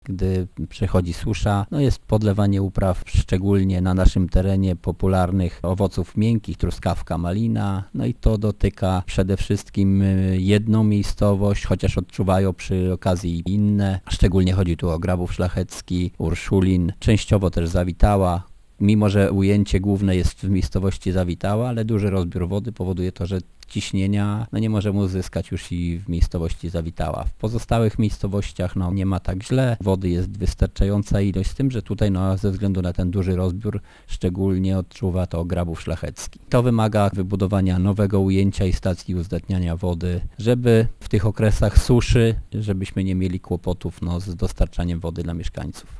Wójt Ryszard Piotrowski uważa, że to powinno zabezpieczyć potrzeby mieszkańców, szczególnie w czasie zwiększonego poboru wody z gminnej sieci: